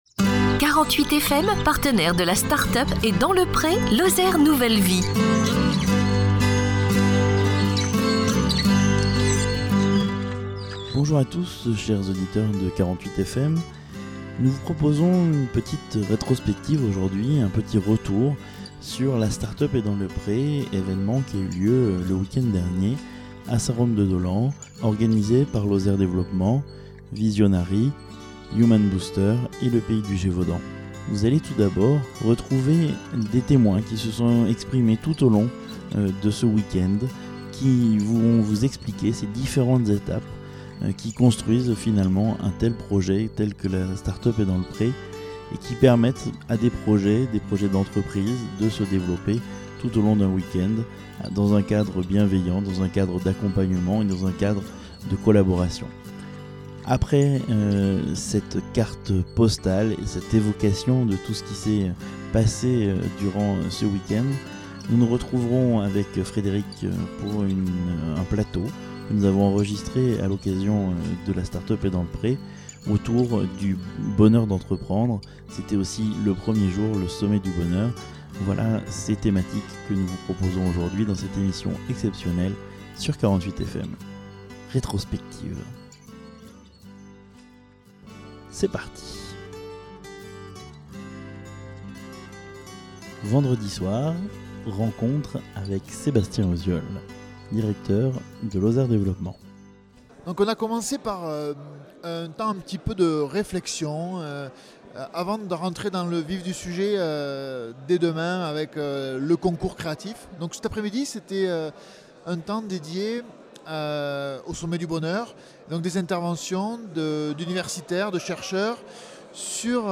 RETROSPECTIVE DU WEEK-END PLATEAU SUR LE BONHEUR D'ENTREPRENDRE